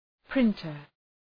Προφορά
{‘prıntər}